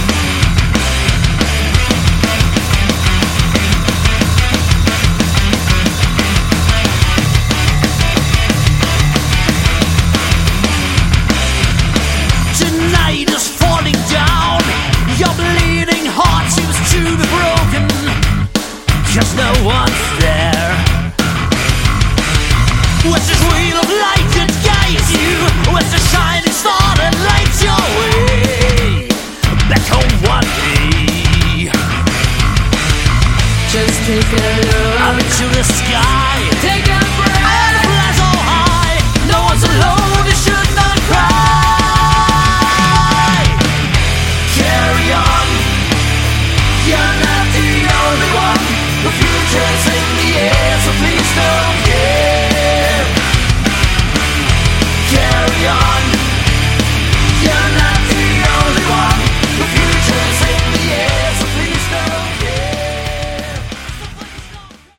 Category: Melodic Metal
guitars
vocals
bass
drums
keyboards